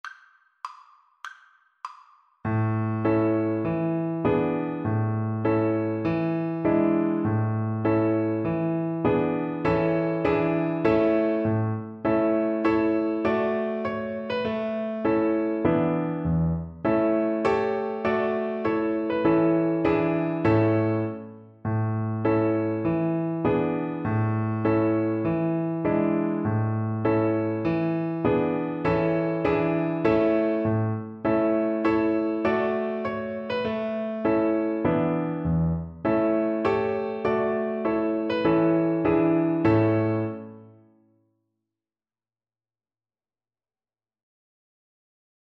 Violin
A major (Sounding Pitch) (View more A major Music for Violin )
Allegretto
2/4 (View more 2/4 Music)
Traditional (View more Traditional Violin Music)